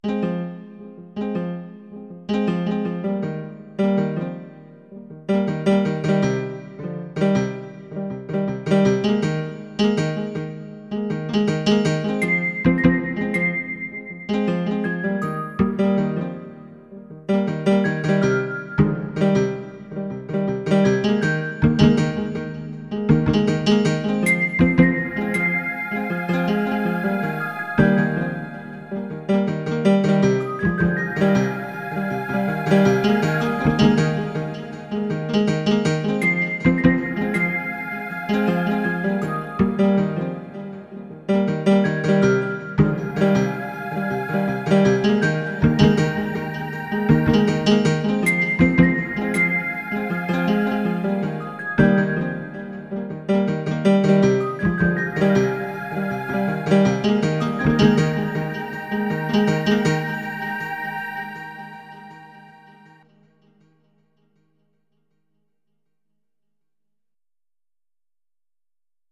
Musique pour le théâtre